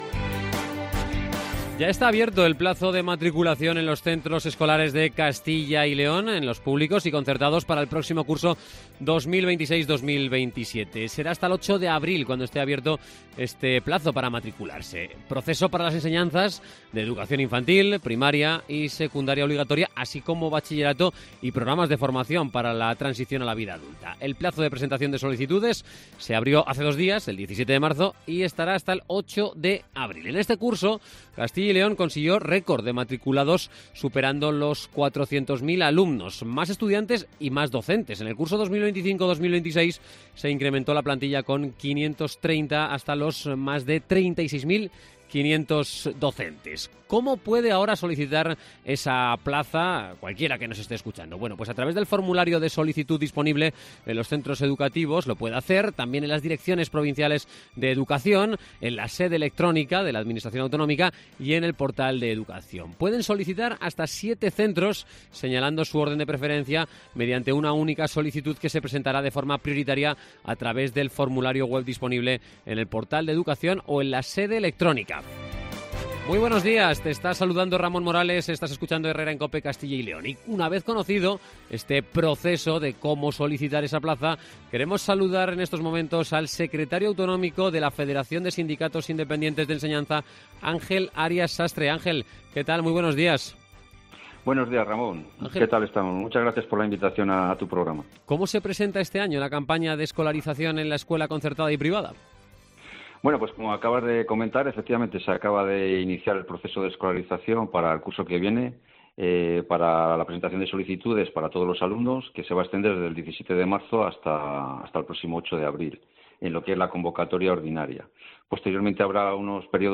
Entrevista en Cope